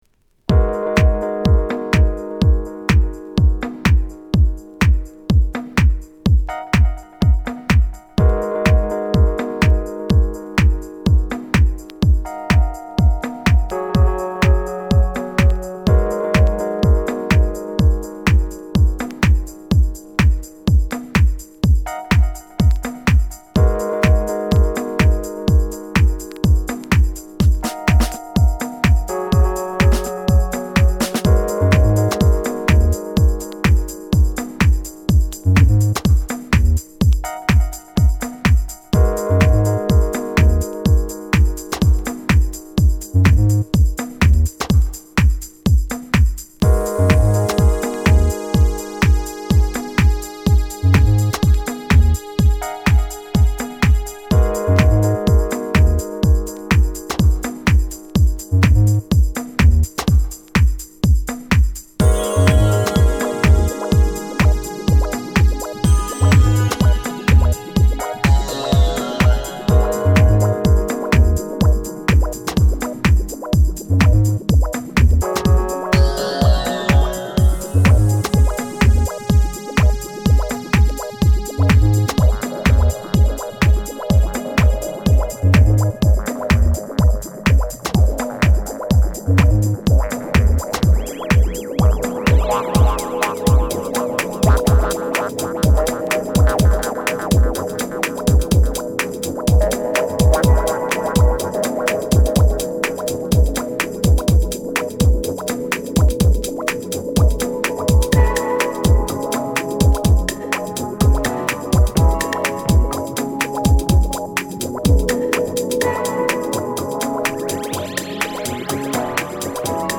TOP > House / Techno > VARIOUS